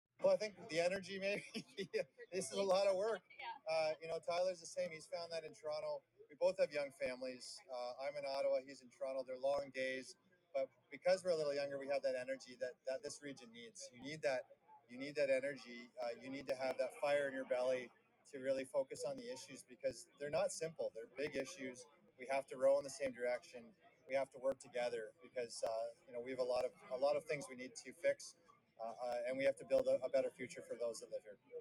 QNet News had the opportunity to interview Allsopp moments before he was declared as the winner of the election.